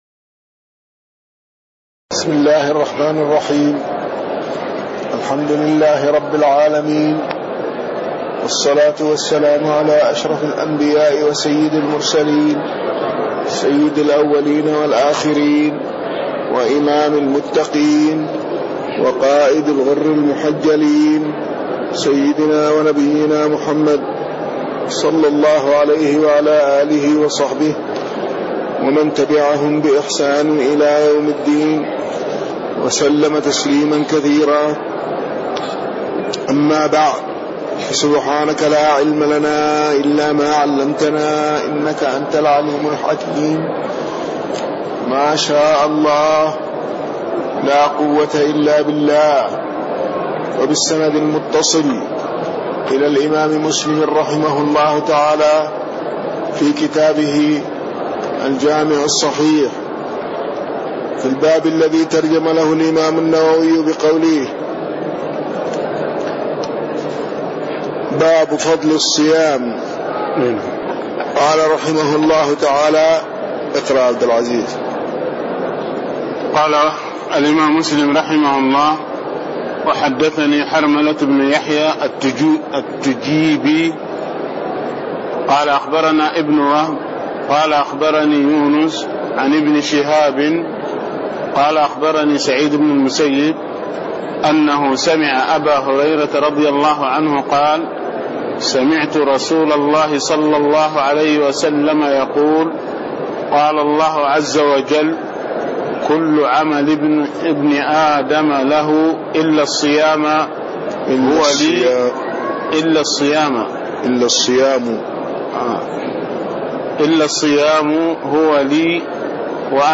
تاريخ النشر ١١ شعبان ١٤٣٣ هـ المكان: المسجد النبوي الشيخ